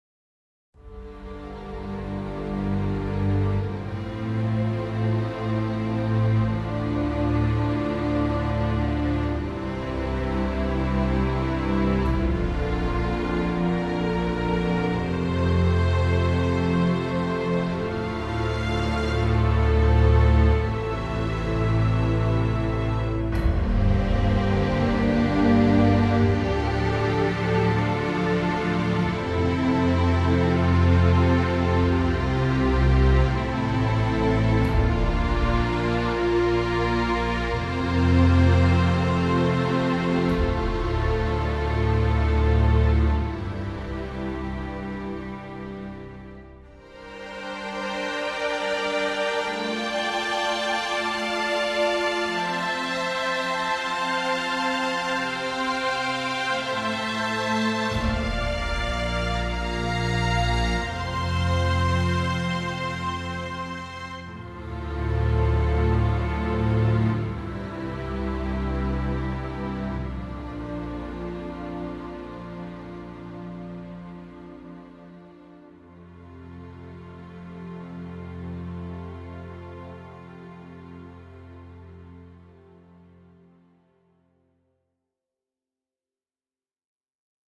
strings only